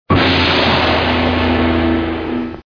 Cartoon Crash 5